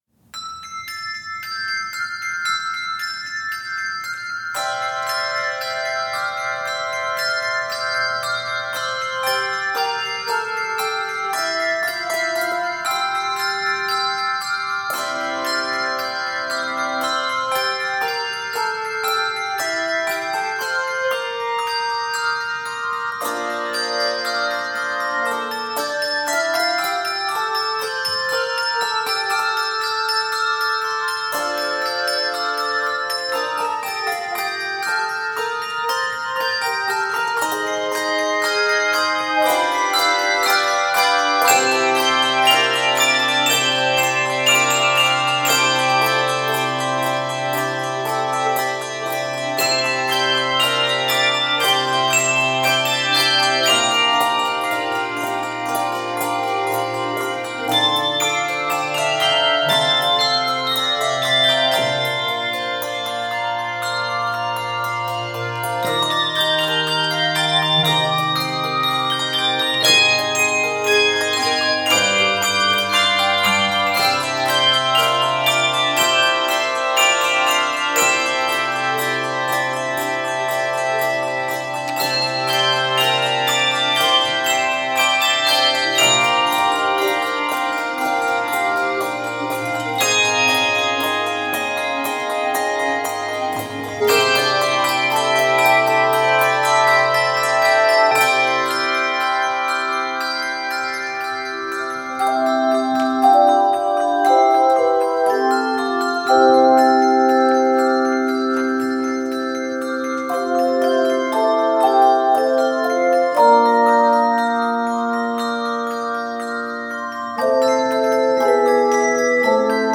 is an energetic, joyous original composition
bass chimes
adds richness and variety in sound